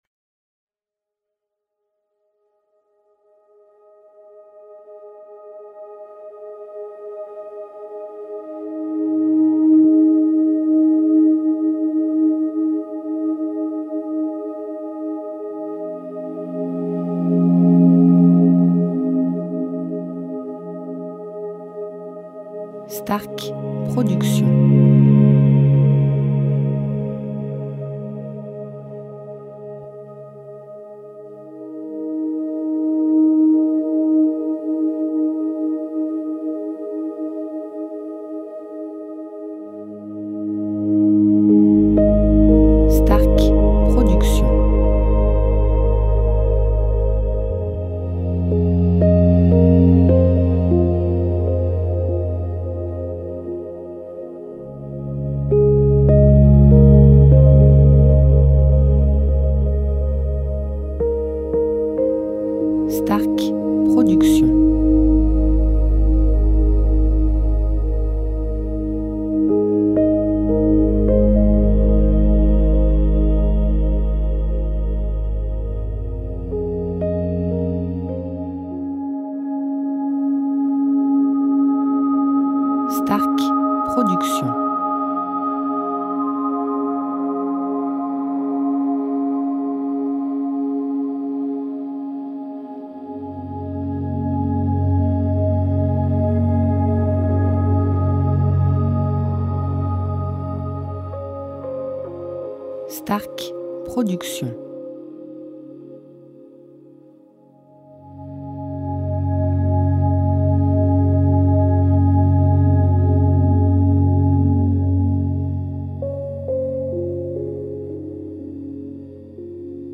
style Sophrologie Méditation Relaxant durée 1 heure